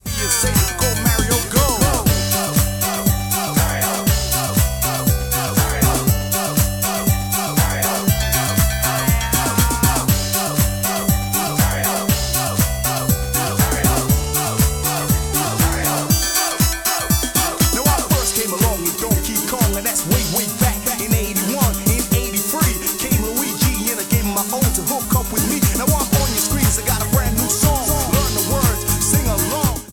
Loopback recorded preview